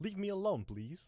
Voice Lines / Dismissive
leave me alone please.wav